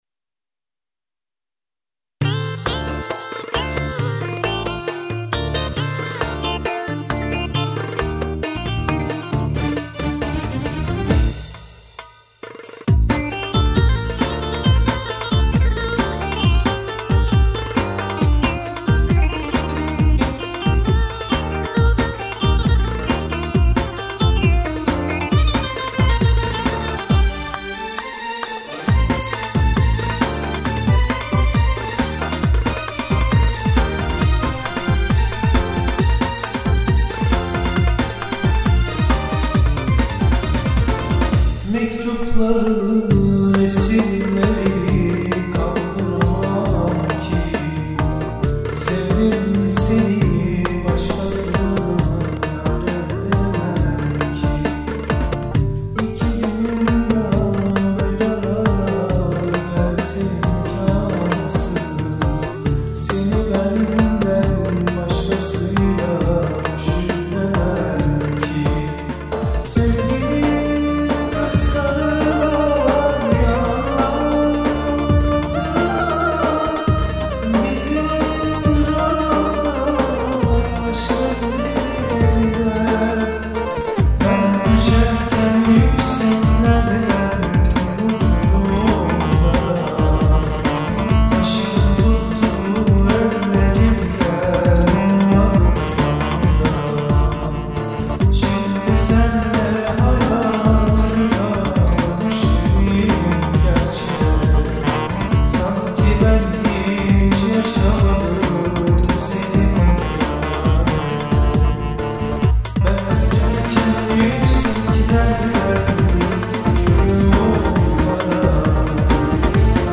Not: Alt yapı tamamen or700V2 ritmi ile hazırlanmıştır.